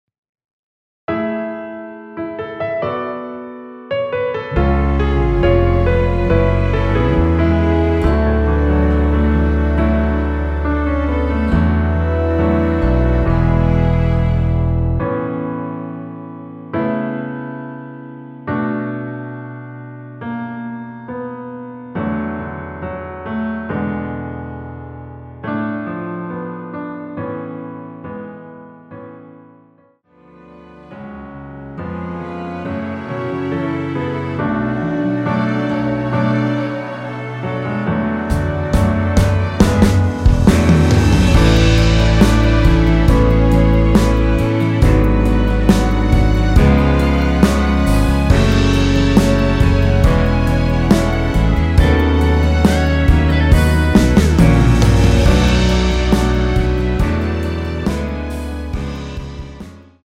원키에서(-3)내린(1절+후렴)으로 진행되는 MR입니다.(본문의 가사와 미리듣기 확인)
앞부분30초, 뒷부분30초씩 편집해서 올려 드리고 있습니다.
중간에 음이 끈어지고 다시 나오는 이유는